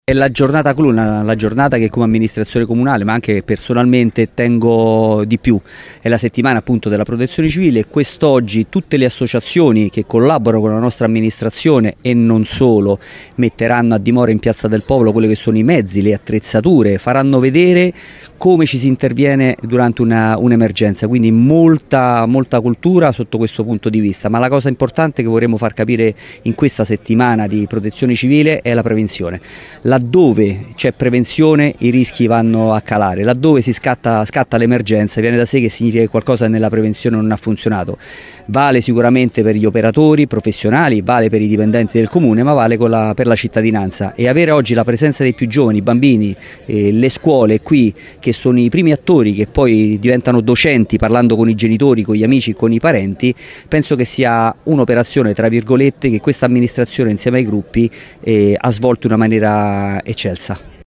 Al taglio del nastro dell’evento clou della Settimana della Protezione Civile, padrona di casa la sindaca di Latina Matilde Celentano, presenti la dottoressa Monica Perna Vicaria del Prefetto, l’assessore regionale Pasquale Ciacciarelli e l’assessore comunale Gianluca di Cocco.